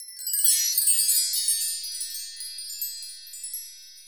Index of /90_sSampleCDs/Roland L-CD701/PRC_Asian 2/PRC_Windchimes